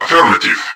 I wanted Volkov & Chitzkoi voices, but wasn't sure what to use, so i decided to take the C&C Remastered RA1 voice lines and triplex them, with some adjustments, to make them cybornetic sounding.